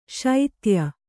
♪ śaitya